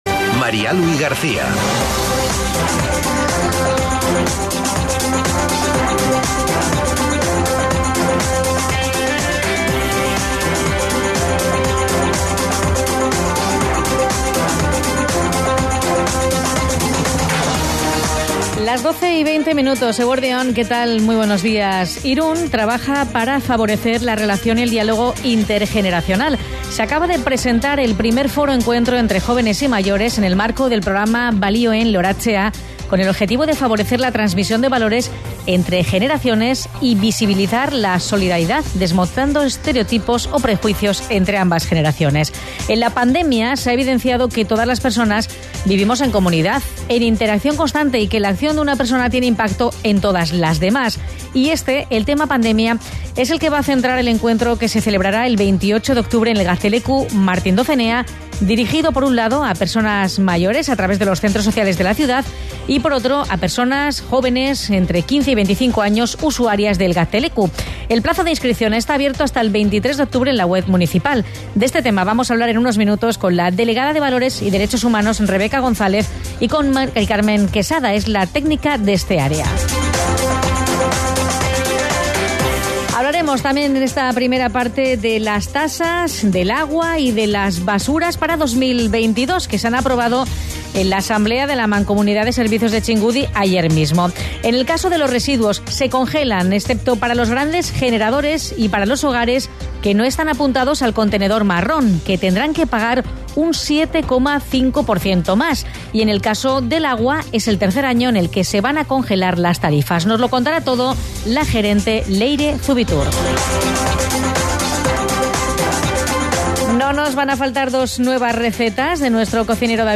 Elkarrizketa Radio Irunen bi hizlariei (1:11:14tik aurrera)